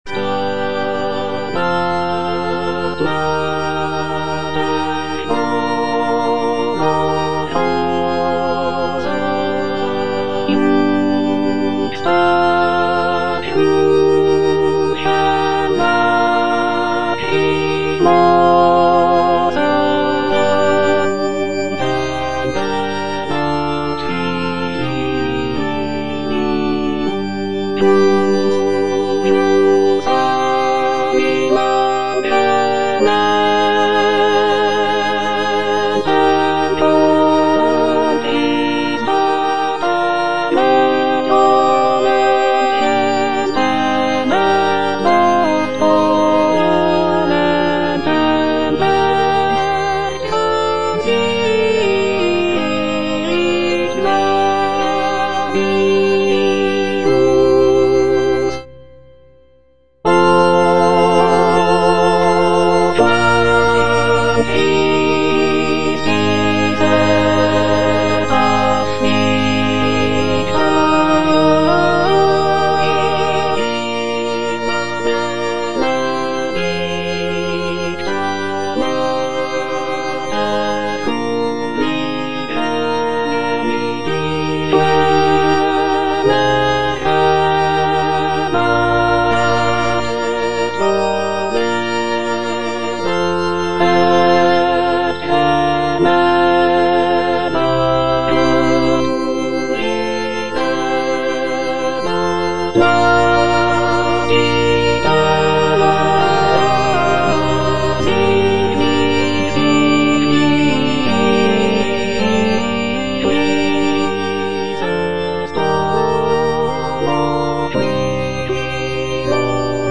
is a sacred choral work
alto II) (Emphasised voice and other voices) Ads stop